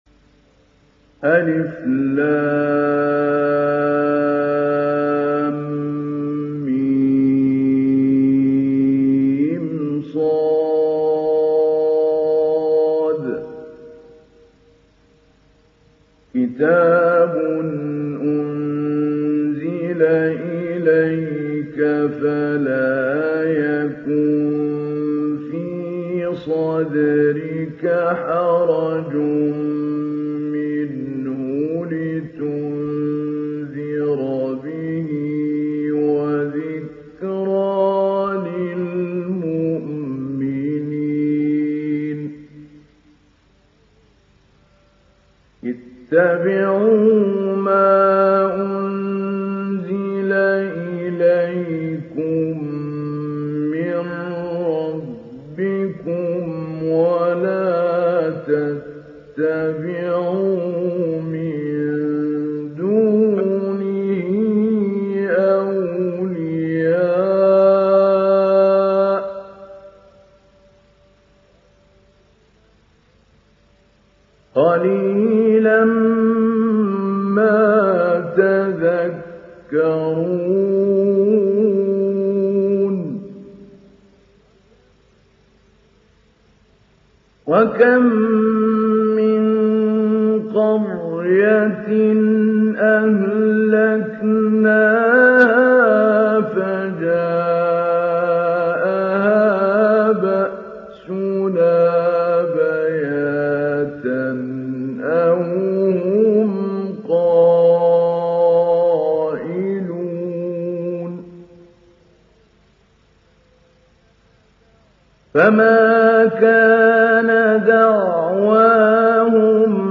Télécharger Sourate Al Araf Mahmoud Ali Albanna Mujawwad